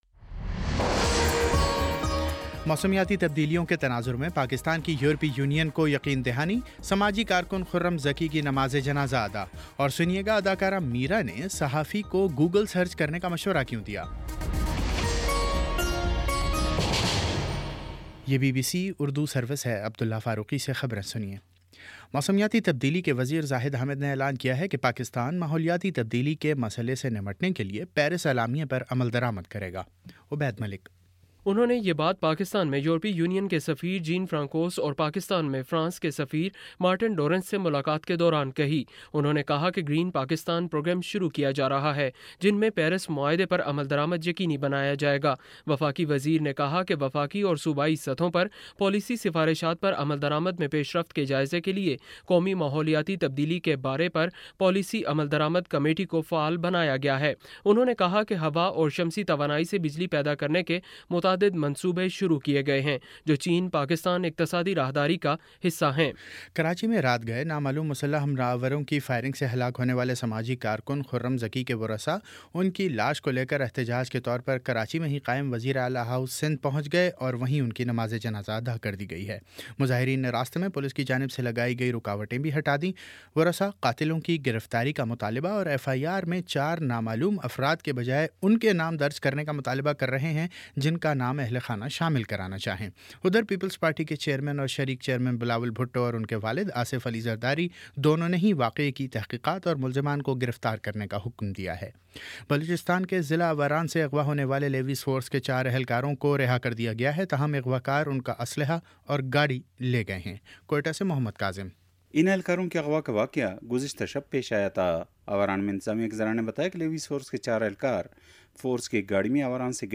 مئی 08 : شام چھ بجے کا نیوز بُلیٹن